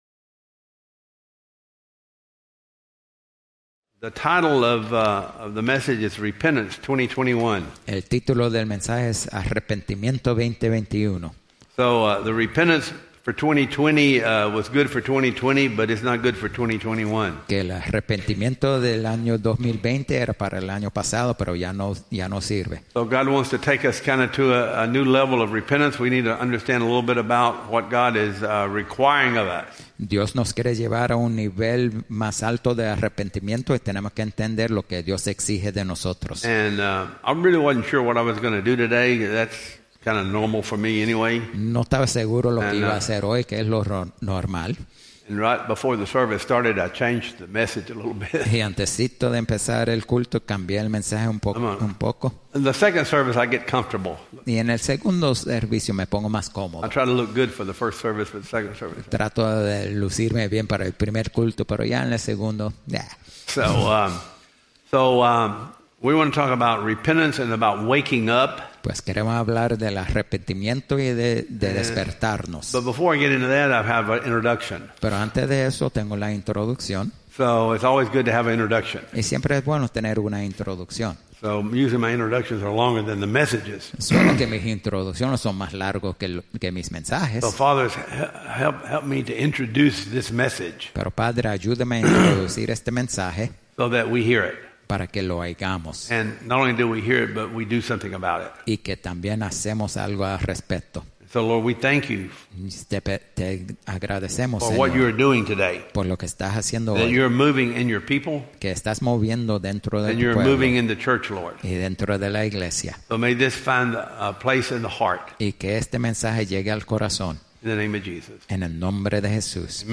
Repentance Service Type: Sunday Service PTWM Family